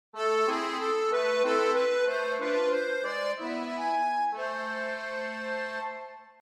Скачать звук аккордеона
Звучание аккордеона в хорошем качестве.
15. Завершение на аккордеоне
akkordeon-zaversh.mp3